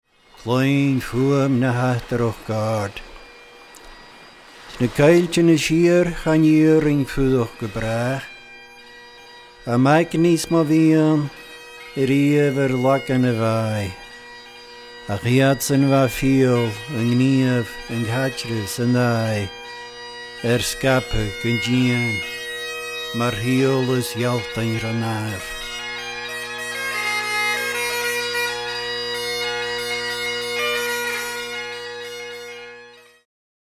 beautiful slow air